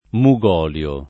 mugolio
vai all'elenco alfabetico delle voci ingrandisci il carattere 100% rimpicciolisci il carattere stampa invia tramite posta elettronica codividi su Facebook mugolio [ mu g0 l L o ] s. m. («olio di mugo») — nome depositato